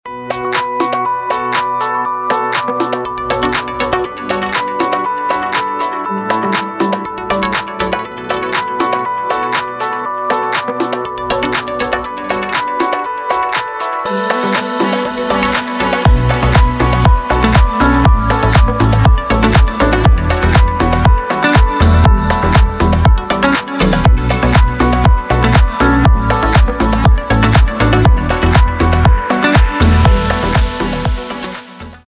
deep house
спокойные
приятные
Теплый deep house порадует ваши ушки)